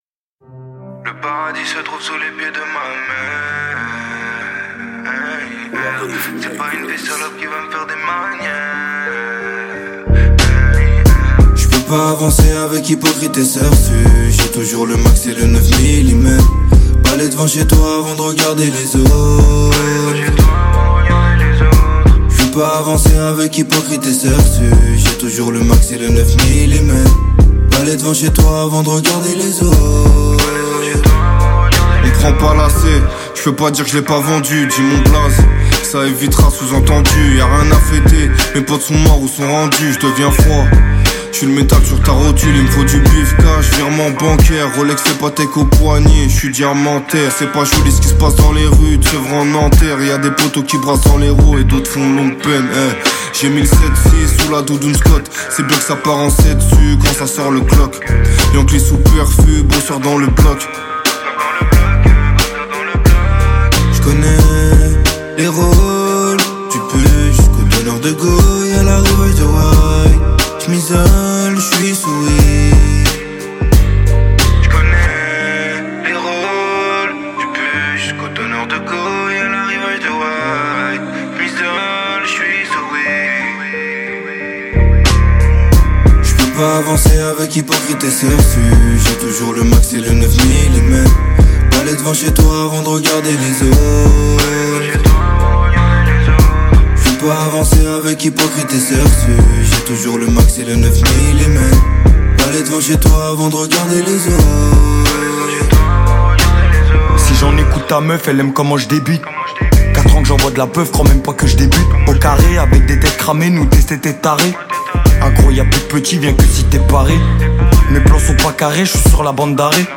Genres : french rap, pop urbaine